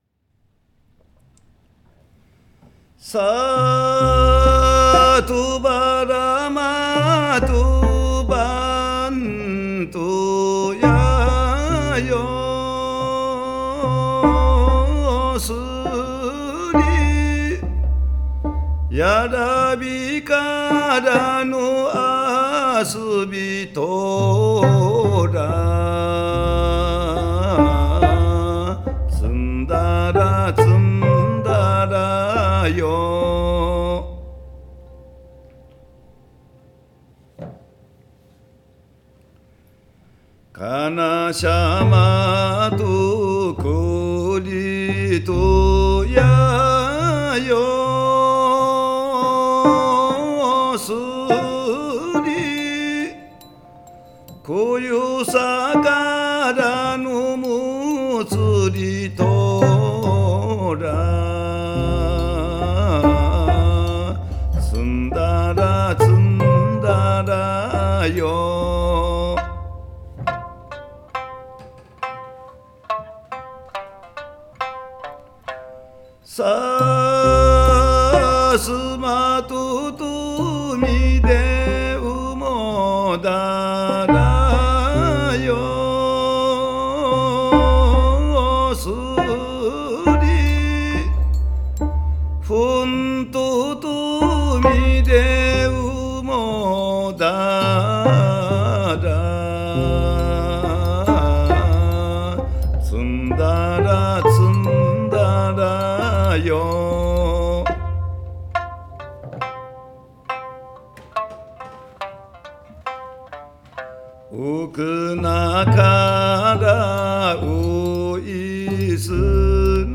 三線と歌唱による沖縄民謡に、西洋音楽の響きを加えた力作！
それから、現代的で神秘的な装いで沖縄民謡を捉えた音響感覚も◎！